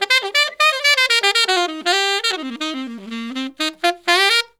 Index of /90_sSampleCDs/Zero-G - Phantom Horns/SAX SOLO 2